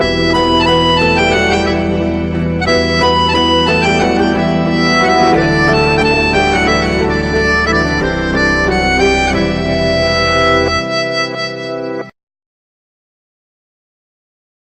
Category: Instrumental Ringtones
Featured in Instrumental Ringtones